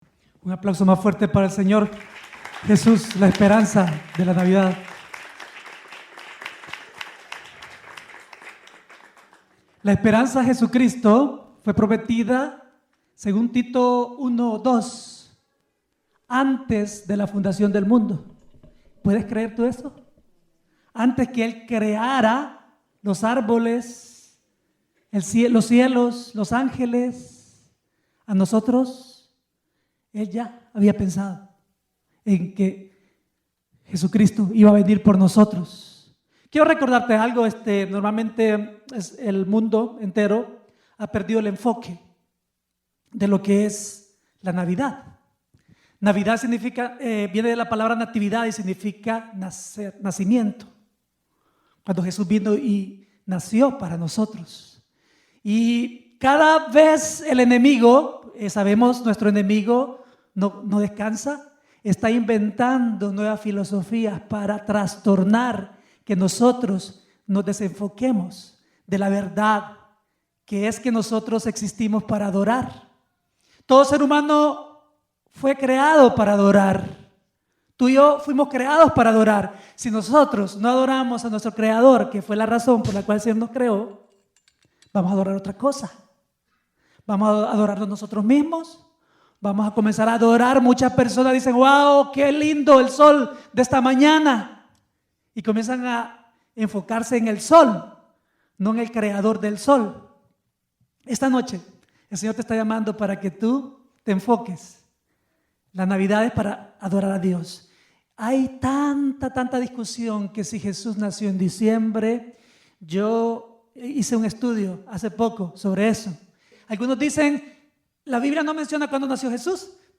predica desde Chicago Norte en una noche llena de nieve